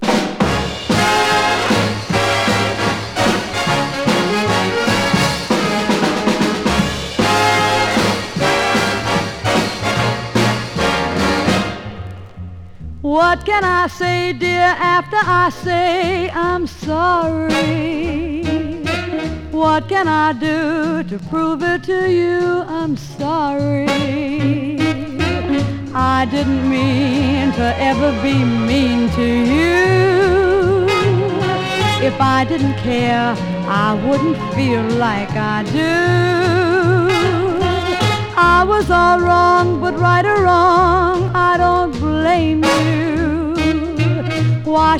Jazz, Pop, Vocal, Easy Listening　USA　12inchレコード　33rpm　Stereo